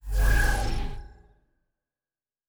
Sci-Fi Sounds / Doors and Portals
Teleport 6_1.wav